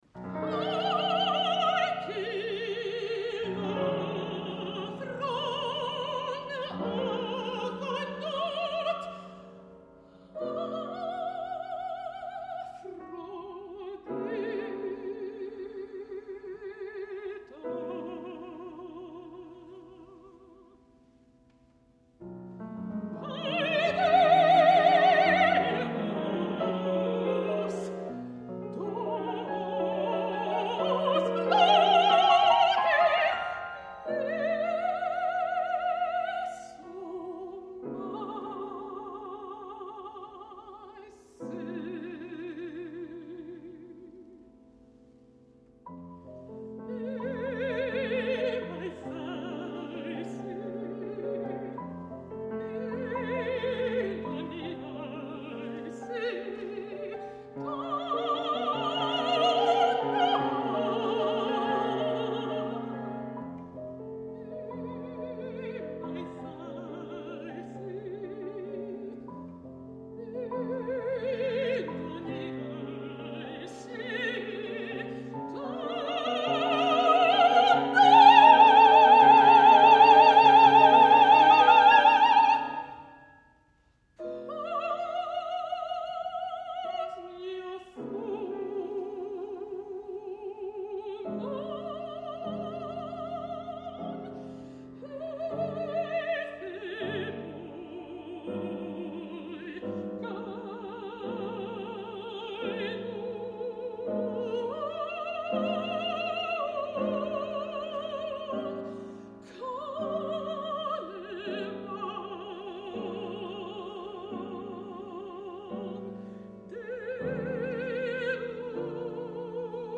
Selections from this work-in-progress were performed with piano accompaniment at Willamette University, Salem, Oregon, on March 13, 2013.